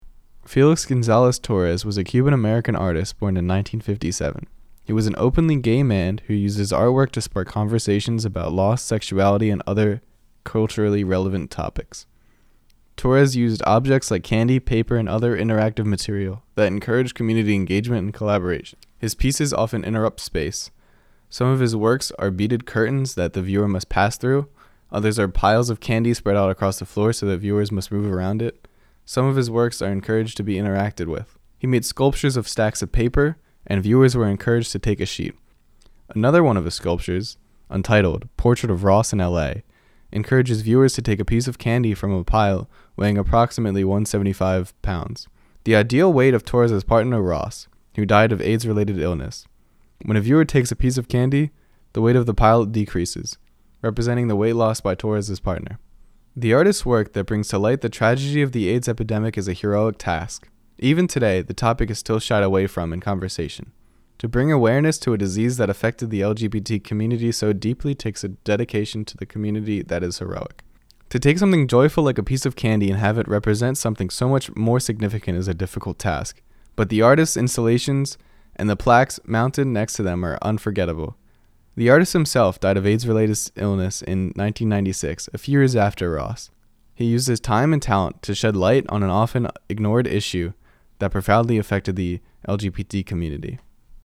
Share Description spoken word Page created on 7/29/2021 6:21:17 PM Last edited 7/30/2021 2:27:33 AM Related pages Felix Gonzalez-Torres